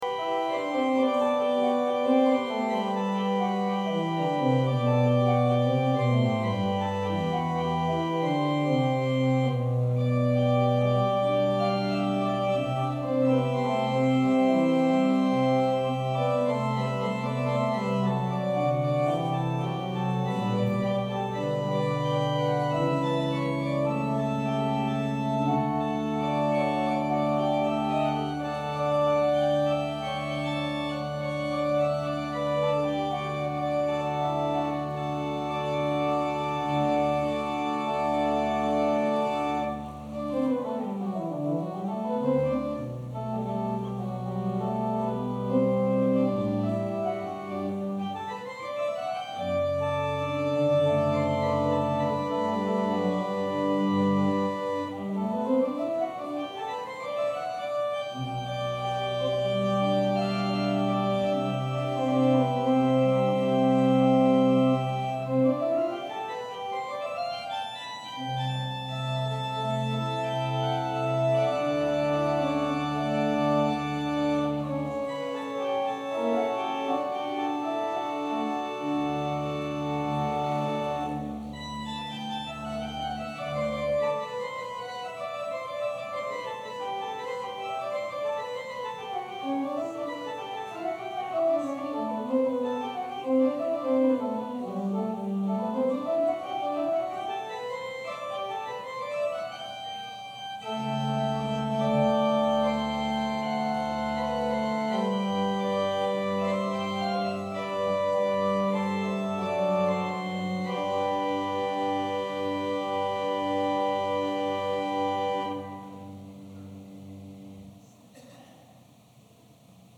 Passage: Luke 4: 21-30 Service Type: Sunday Service Scriptures and sermon from St. John’s Presbyterian Church on Sunday